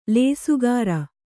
♪ lēsugāra